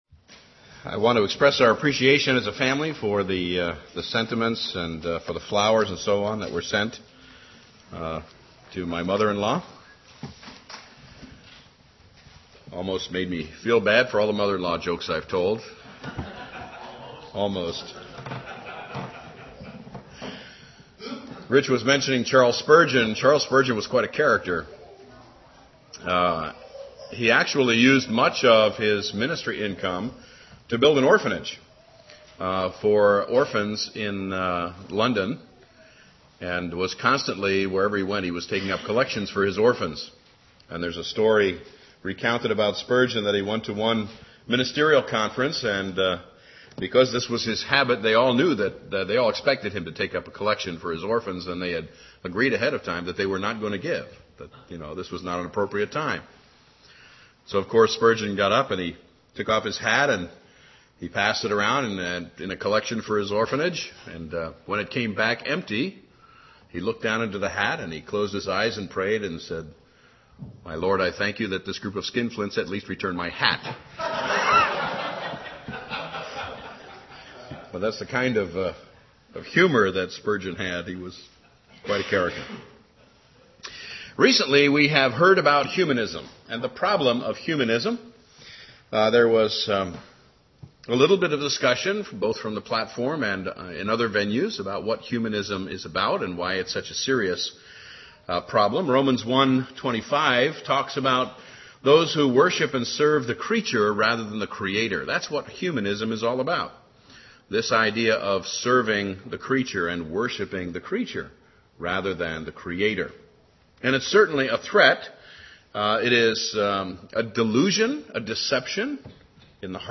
In this sermon, the preacher begins by reading from Joshua chapter 24, emphasizing the importance of fearing and serving the Lord in sincerity and truth. He warns against the dangers of humanism, which is the worship of the creature rather than the creator. The preacher also shares a humorous anecdote about Charles Spurgeon, a well-known preacher who used his ministry income to build an orphanage.